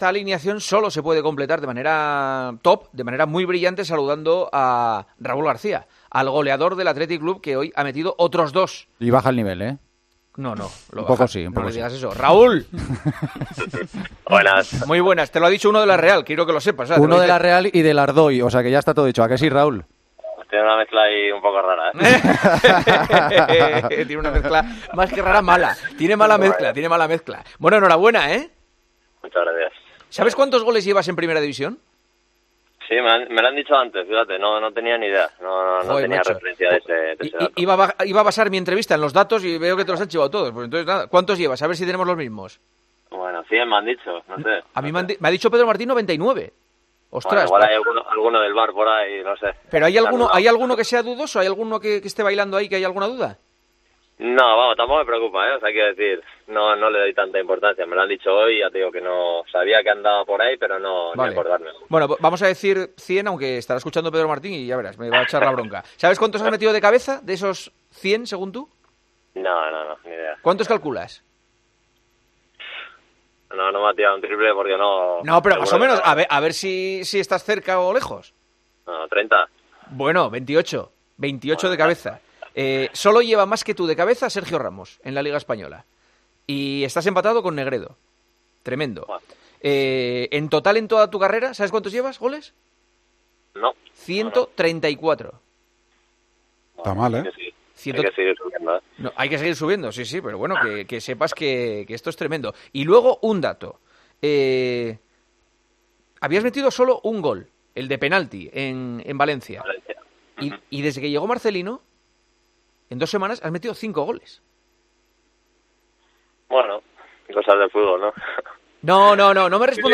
Entrevista al jugador del Athletic después de marcar dos goles en la goleada 5-1 al Getafe.